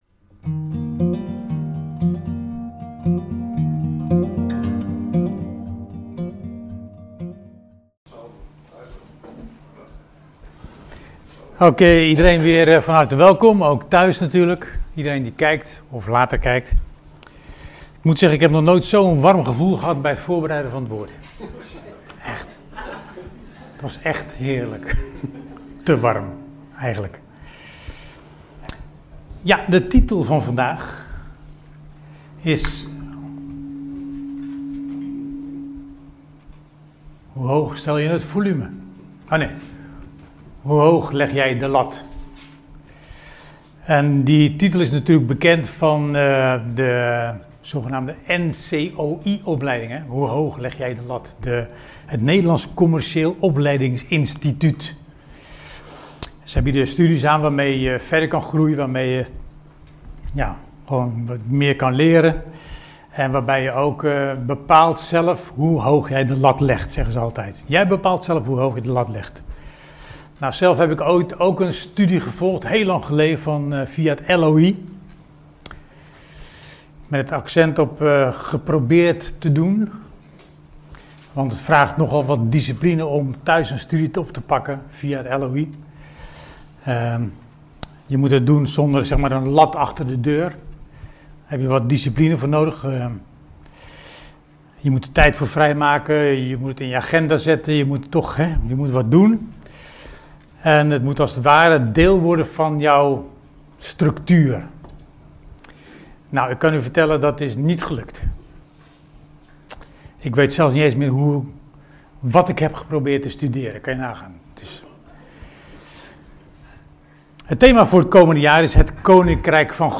in Preken